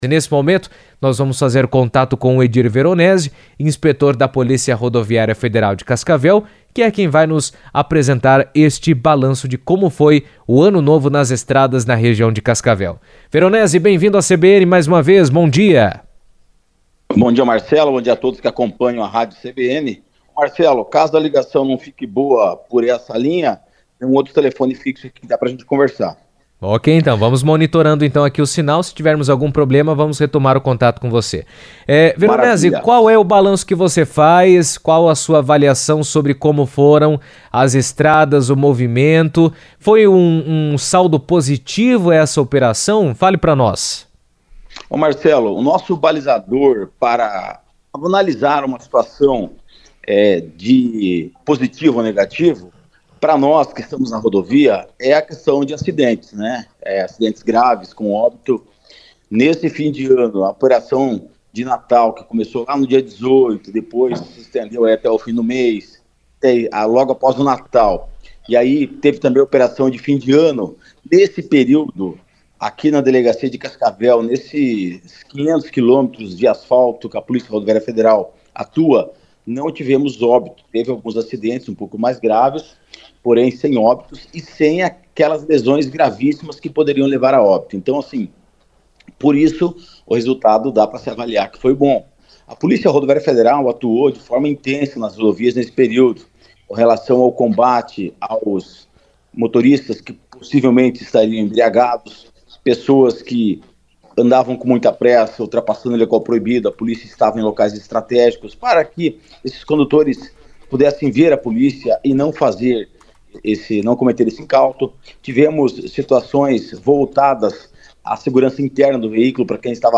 Ele falou sobre o assunto em entrevista à CBN.